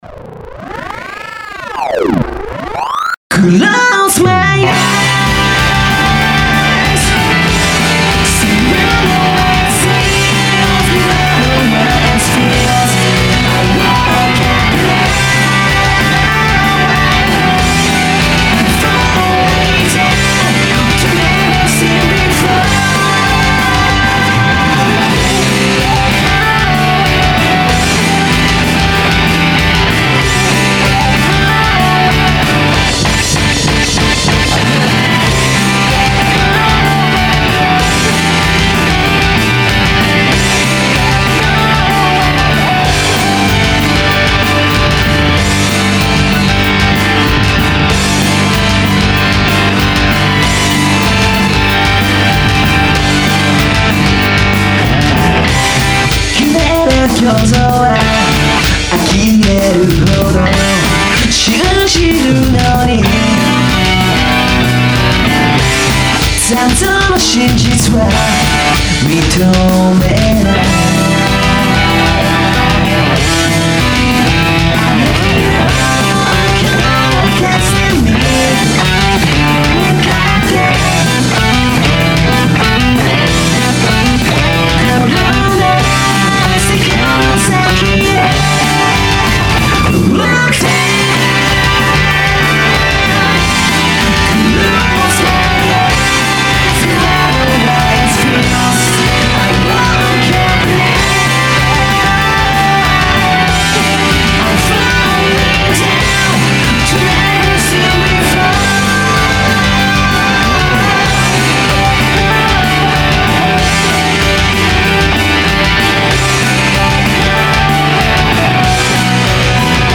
お客様自身によるミックス・マスタリングの音源：